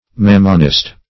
Mammonist \Mam"mon*ist\, n.